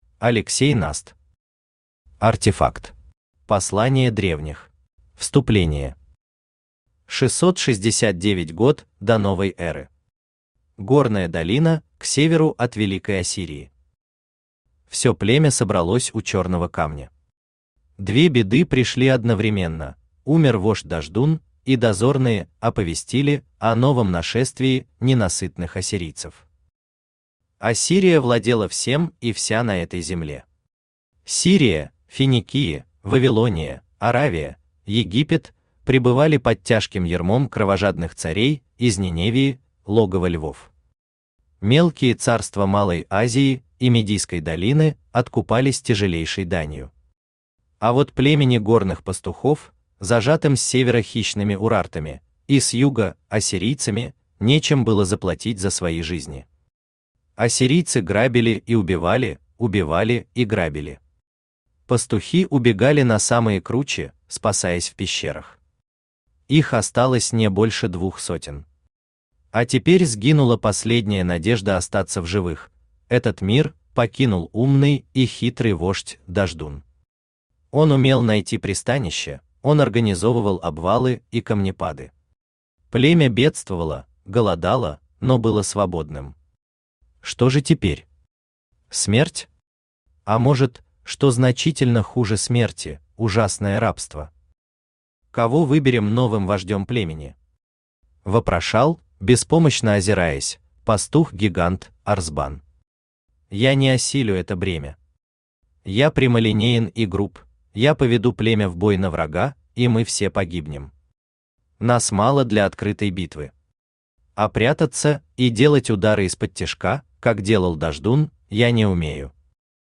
Aудиокнига Артефакт. Послание древних Автор Алексей Николаевич Наст Читает аудиокнигу Авточтец ЛитРес.